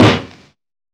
• Boom Snare Drum Sample A Key 69.wav
Royality free acoustic snare tuned to the A note. Loudest frequency: 981Hz
boom-snare-drum-sample-a-key-69-UUZ.wav